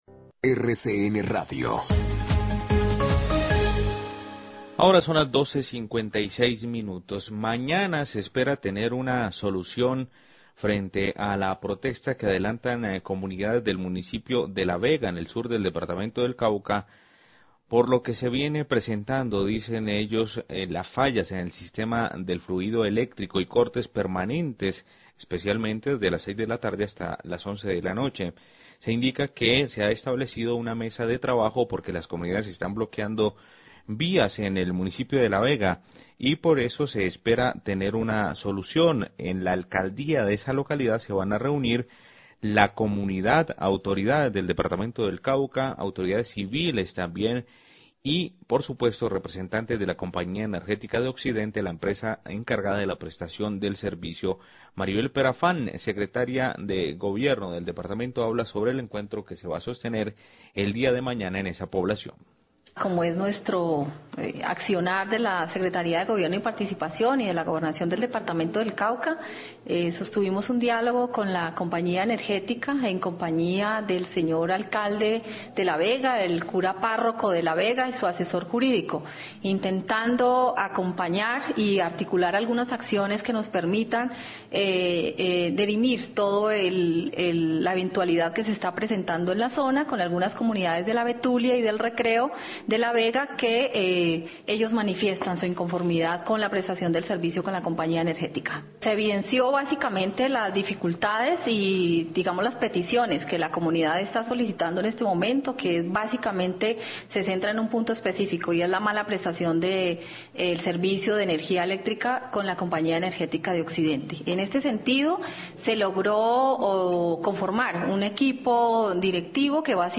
Radio
Mañana se espera tener una solución frente a la protesta que adelantan comunidades de La Vega por las fallas y cortes permanentes en el fluido eléctrico. Se ha establecido una mesa de diálogo entre la comunidad, autoridades del Cauca, autoridades civiles y representantes de la Compañía Energética. Declaraciones  de la Secretaria de Gobierno, Maribel Perafán.